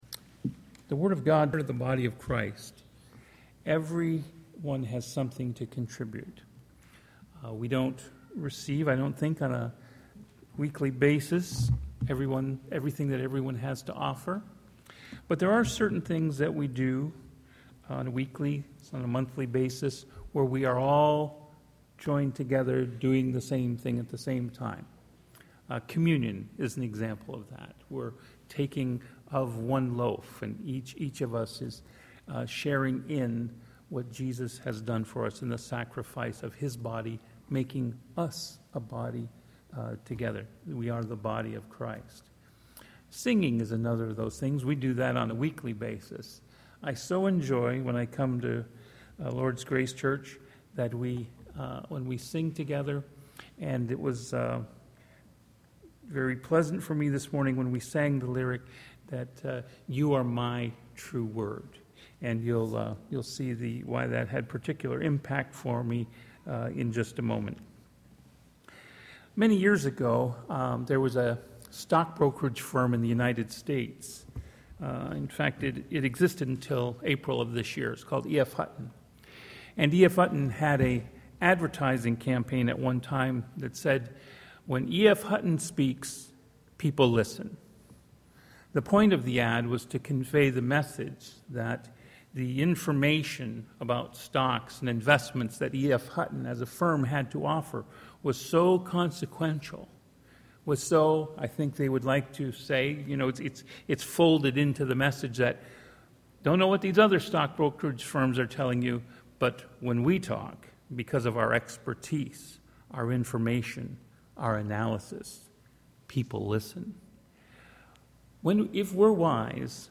Sermons | Lord's Grace Church